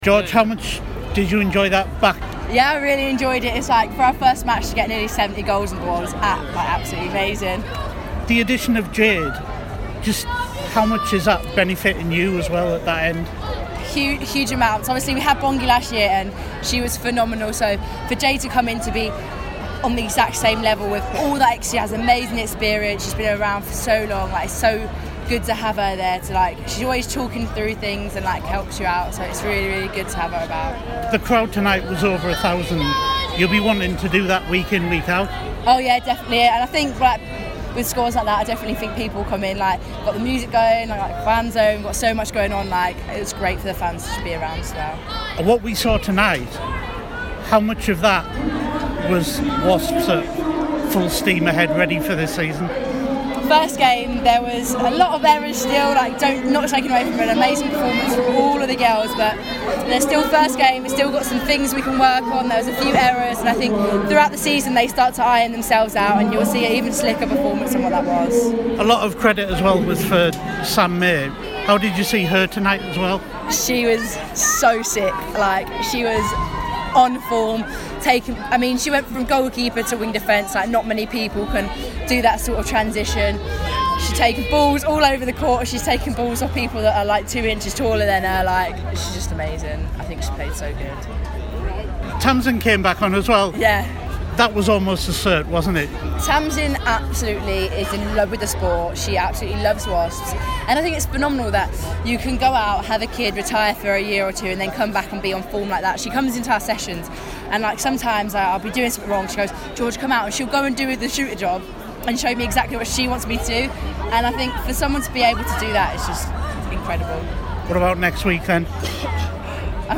spoke to me after Wasps 68-43 win against Storm at the Ricoh Arena